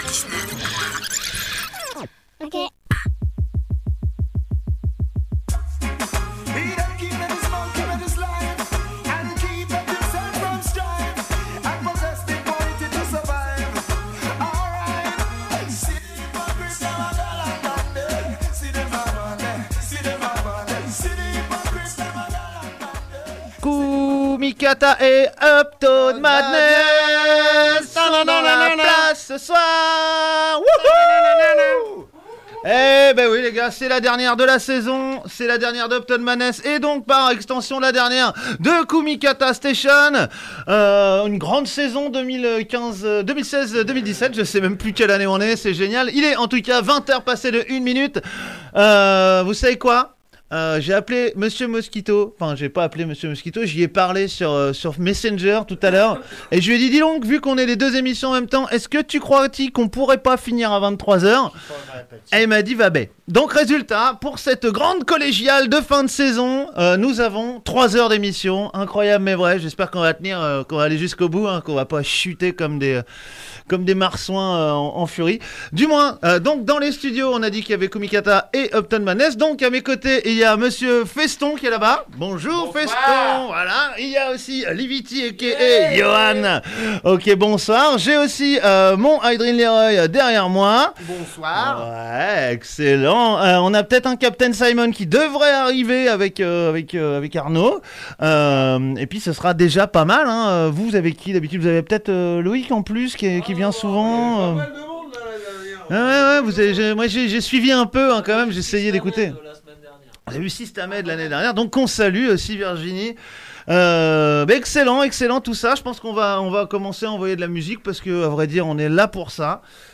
grosses sélections Roots et Dub inna Sound System Style